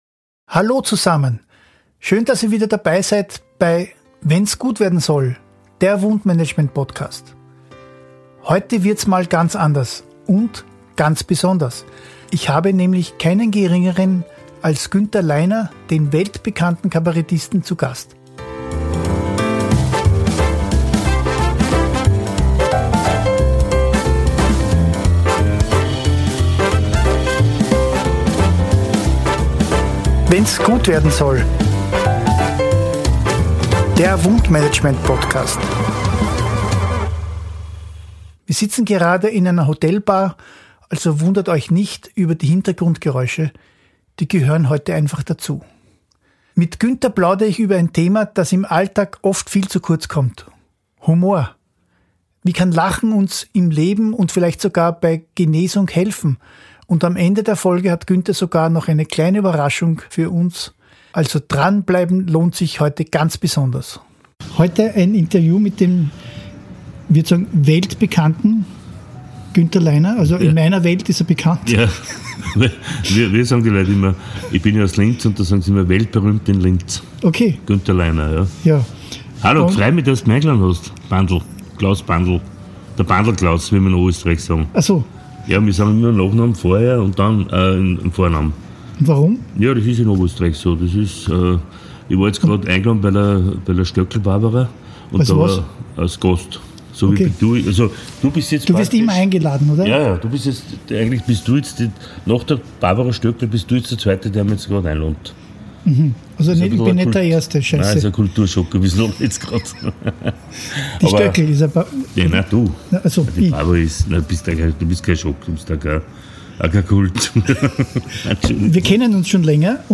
Treffen sich zwei Kabarettisten in einer Hotelbar…